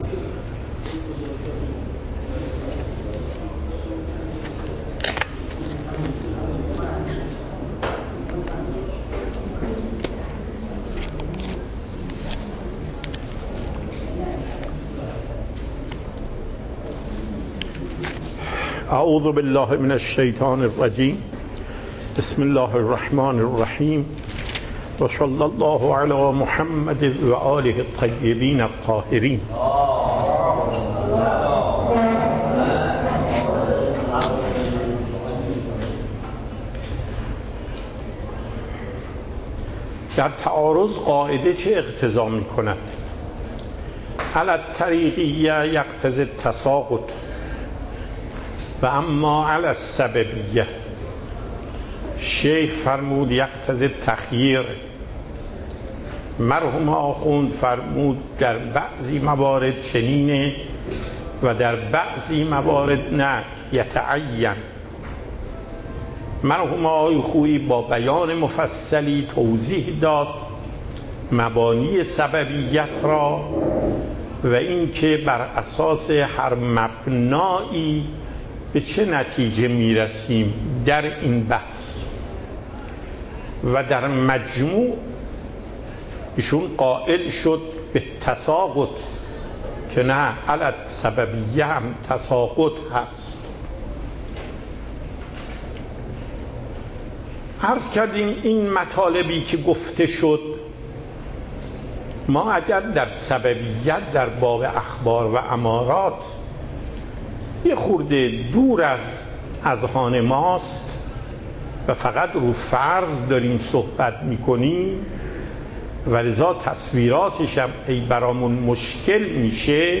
پخش صوت درس: